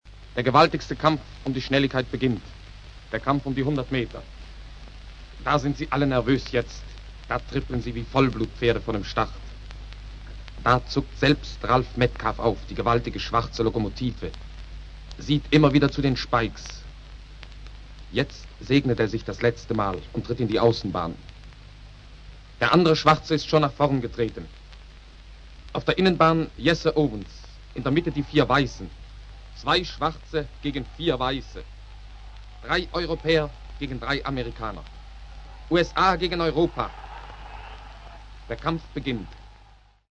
Beim Aufruf dieser Seite sollte schon ein Satz aus dem Munde von Heinz Florian Oertel zu hören gewesen sein, der uns mitten hinein führt in das Thema der VII. Boltenhagener Tage für akustische Medien: